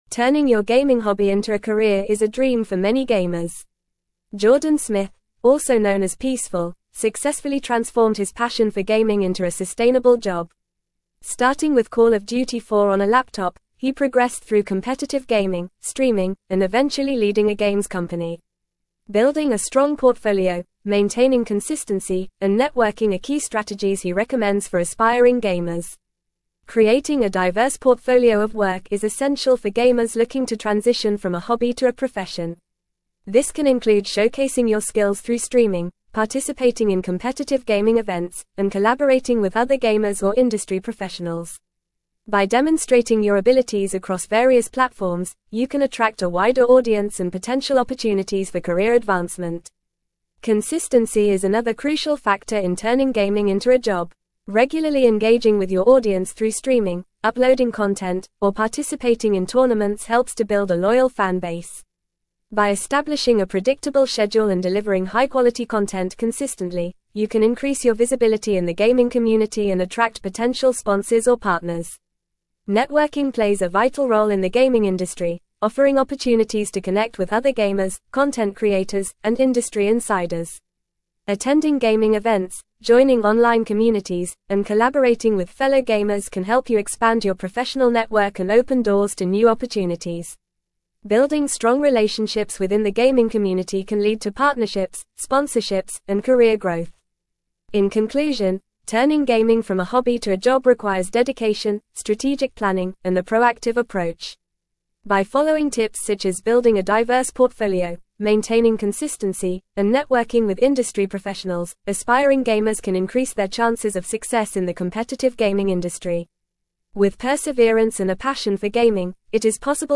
Fast
English-Newsroom-Advanced-FAST-Reading-Turning-Gaming-Passion-into-Career-Success-Key-Strategies.mp3